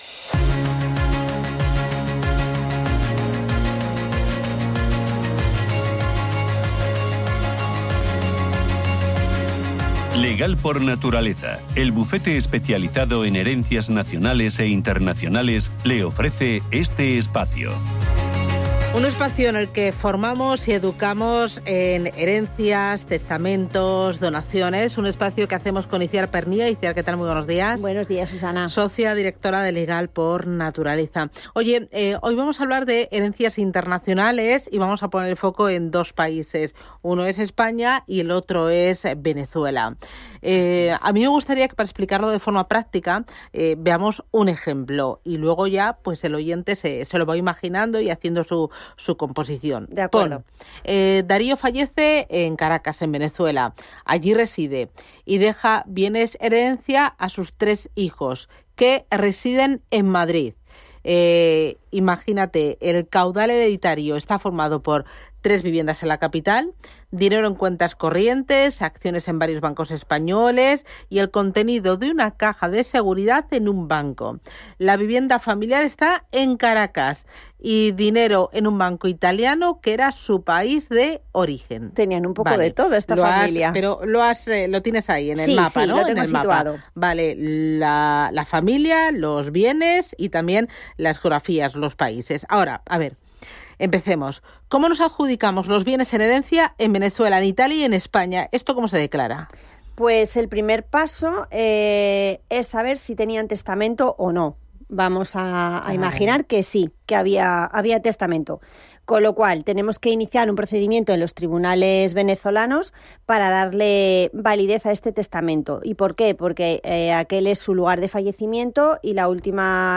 En este espacio analizamos situaciones concretas y la experta también resuelve las dudas de nuestros oyentes.